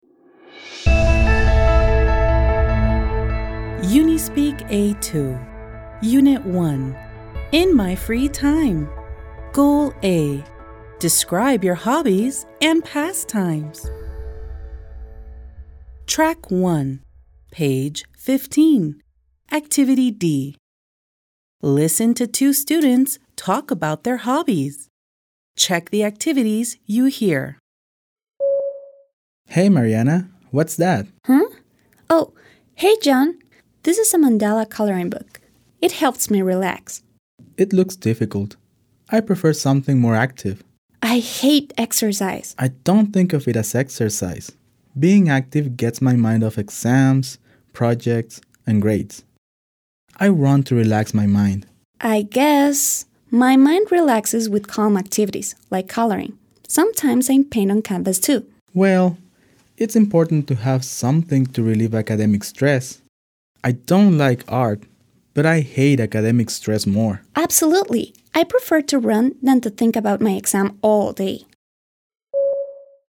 Listen to two students talk about their hobbies. Check the activities you hear.